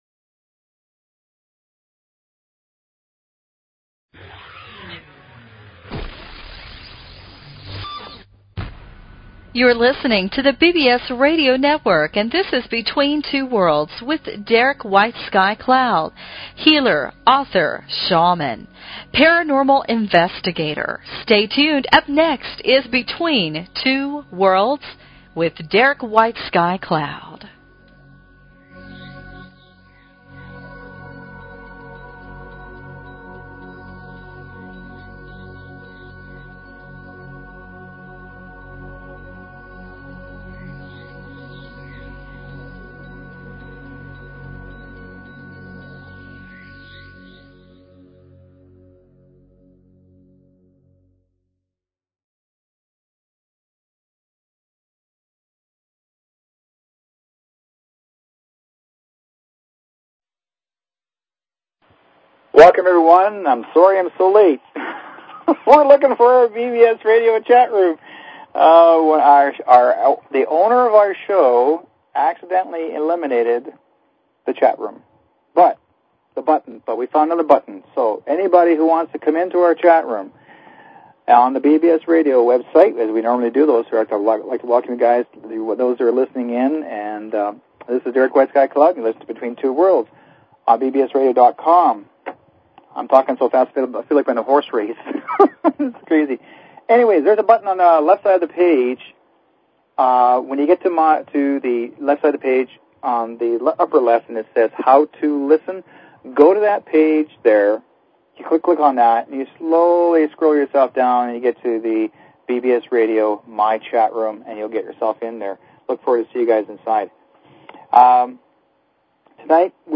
Talk Show Episode, Audio Podcast, Between_Two_Worlds and Courtesy of BBS Radio on , show guests , about , categorized as